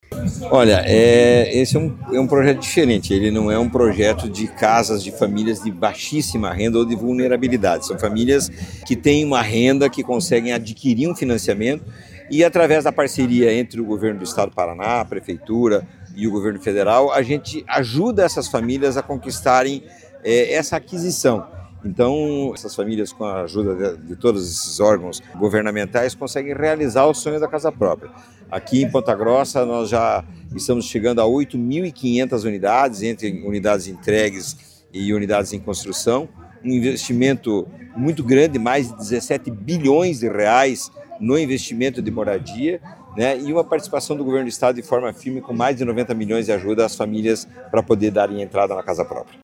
Sonora do presidente da Cohapar, Jorge Lange, sobre a entrega de 220 casas em Ponta Grossa